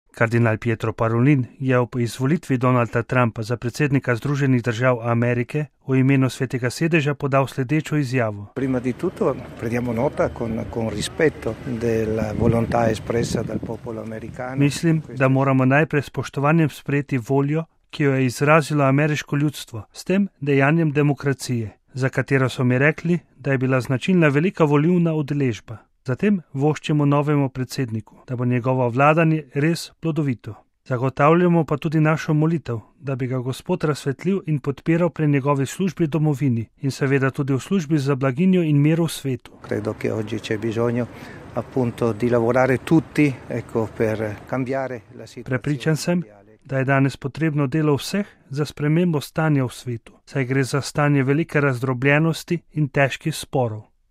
Izjava kardinala Pietra Parolina ob izvolitvi Donalda Trumpa za predsednika ZDA